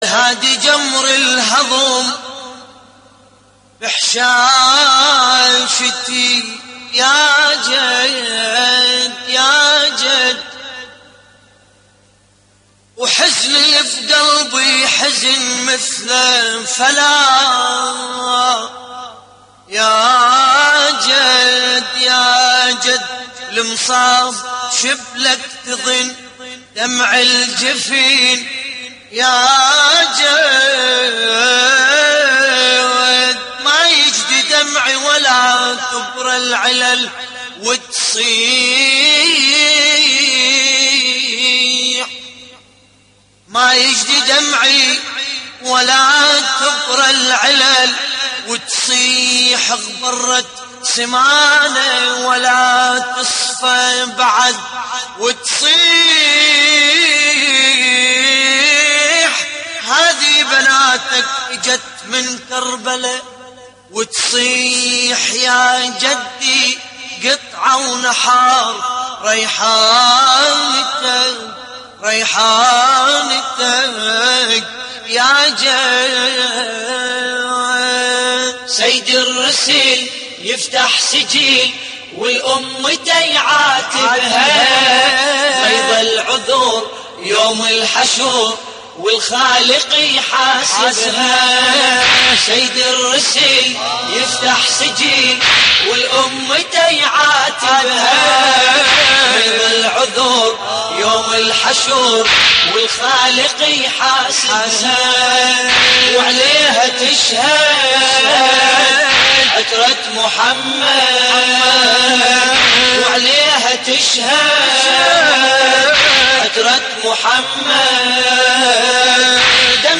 موقع يا حسين : اللطميات الحسينية سيد الرسل يفتح سجل ولأمته يعاتبها - استديو لحفظ الملف في مجلد خاص اضغط بالزر الأيمن هنا ثم اختر (حفظ الهدف باسم - Save Target As) واختر المكان المناسب